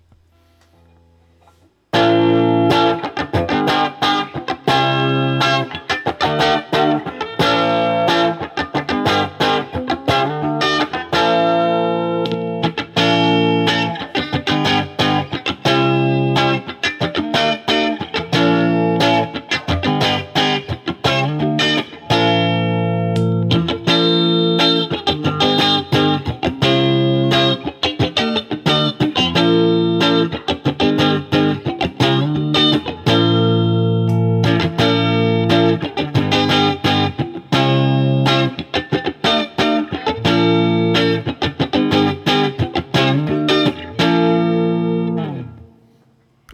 All recordings in this section were recorded with an Olympus LS-10.
1997 S-100 All Pickups
Next, I take both guitars and through the Tiny Tweed setting (basically a Fender Champ type of sound), I run through a simple barre chord progression on each guitar using each of the possible pickup positions. Both guitars have all knobs on 10 for these recordings.
Note that the 1997 S-100 has an addition sound thanks to its phase switch, and I gotta say that even though the NS is chimier, in my opinion the phase switch makes up for this by expanding the sonic capabilities of the older guitar.